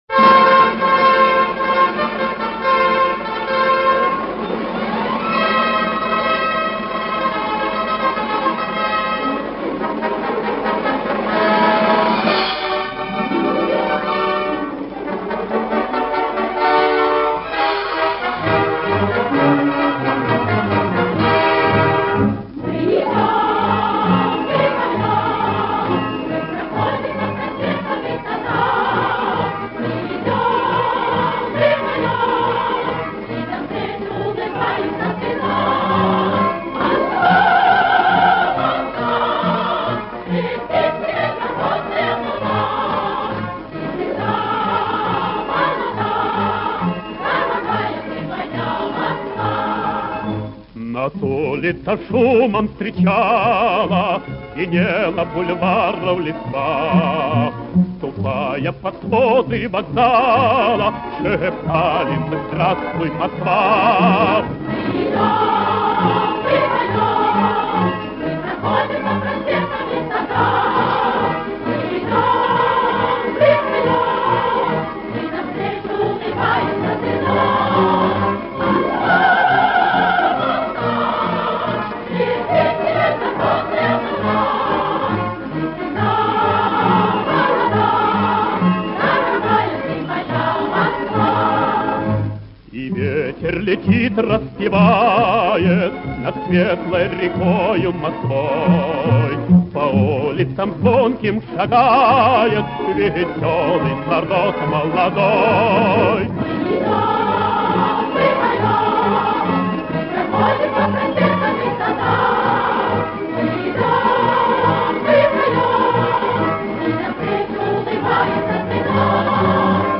Традиционно бодрая и радостная советская песня
хор и оркестр.